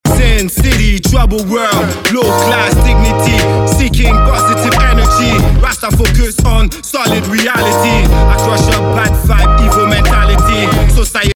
hard-hitting Reggae-Hip Hop anthem
The heavy bassline and hypnotic groove give
a rebellious yet uplifting energy